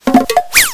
SFX一溜小跑 000音效下载
SFX音效